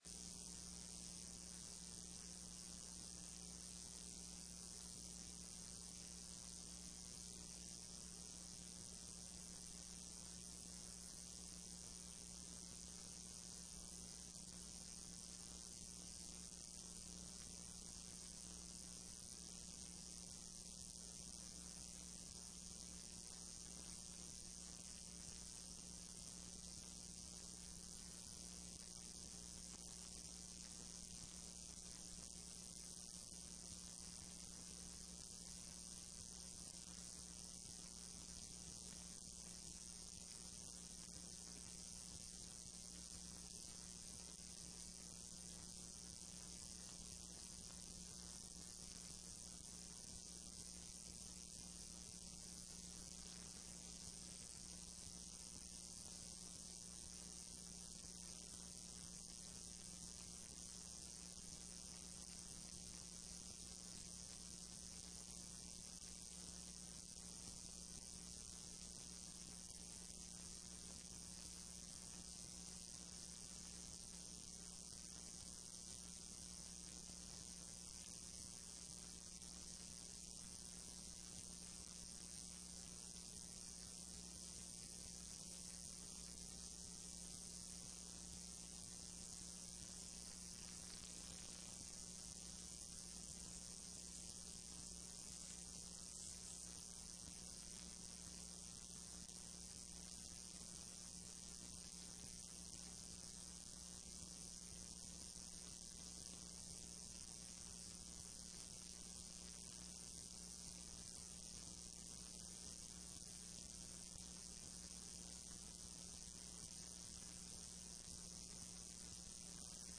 Sessão solene de Posse dos novos juízes